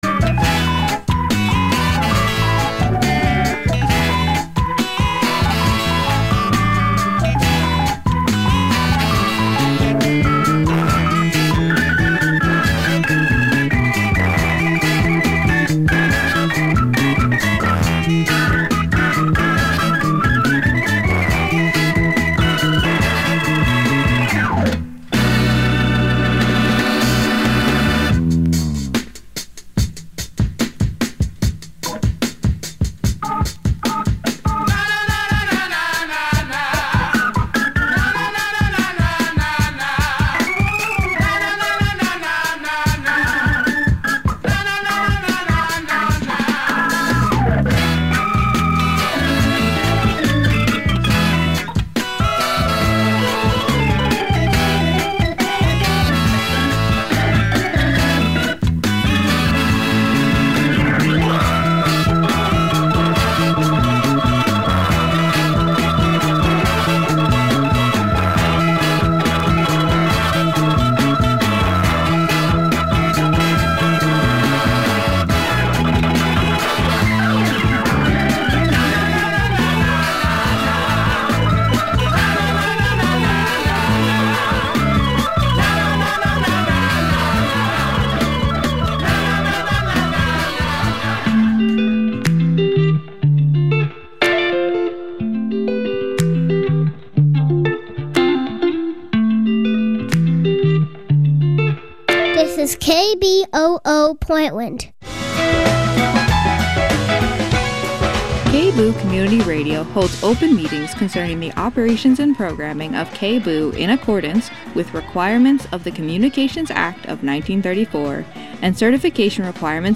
INVESTIGATIVE News Radio with host